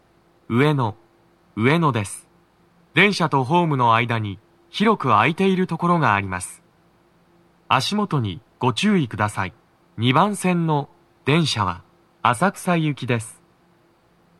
足元注意喚起放送が付帯されており、多少の粘りが必要です。
2番線 浅草方面 到着放送 【男声
到着放送1